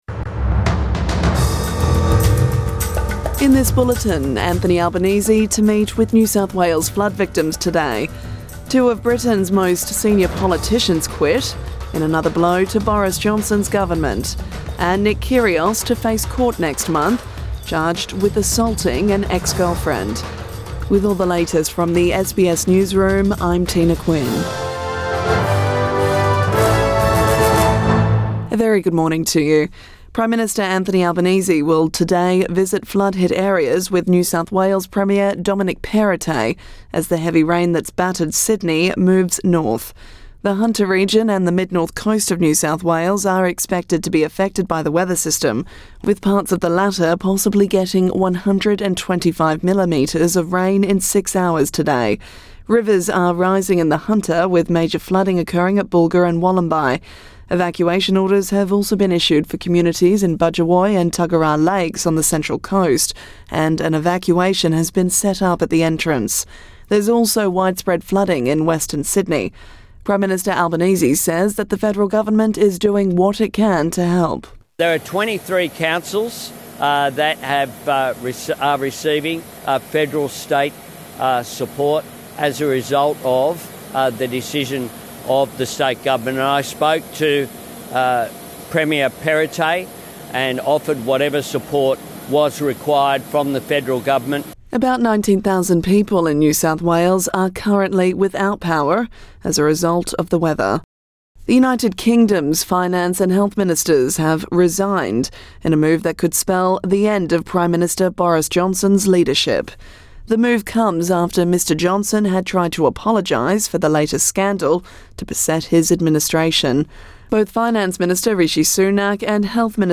AM bulletin 6 July 2022